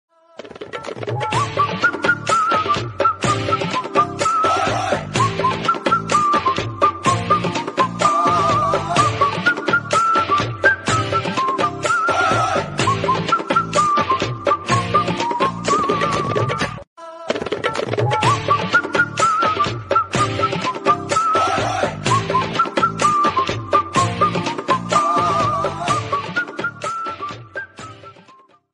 romantic flute version